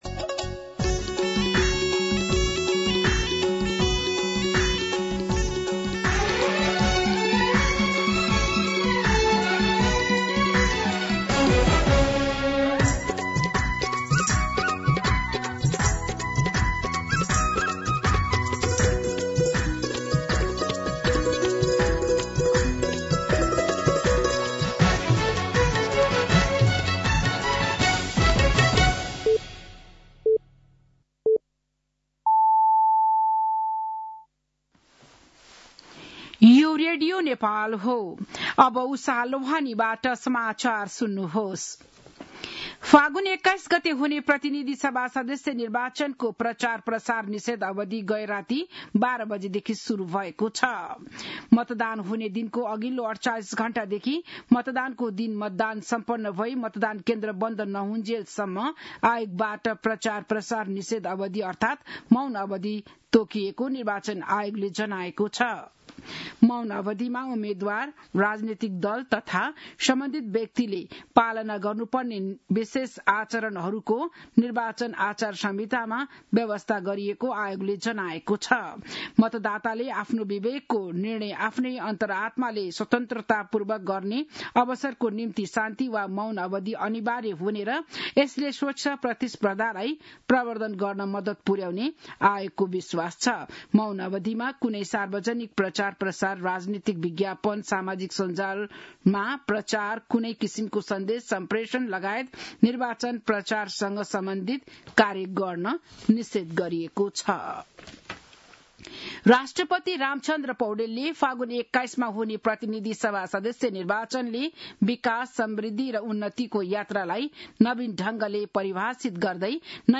बिहान ११ बजेको नेपाली समाचार : १९ फागुन , २०८२